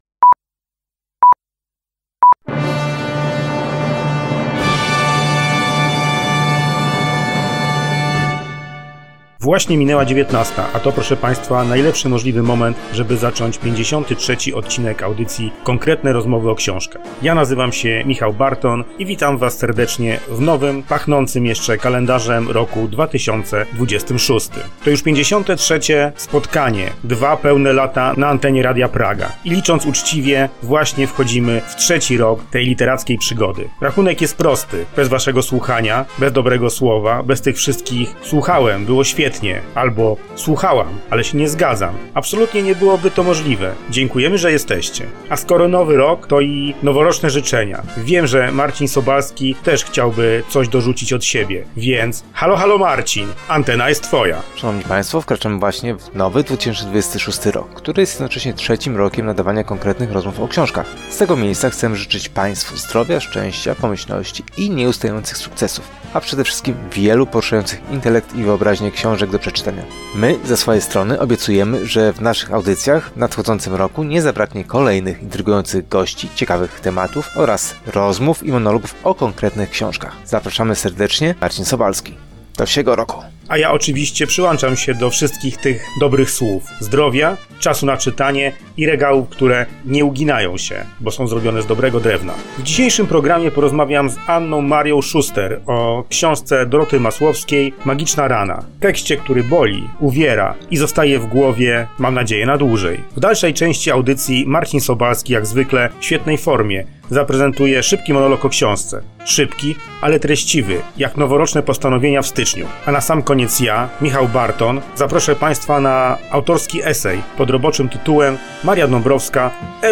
esej radiowy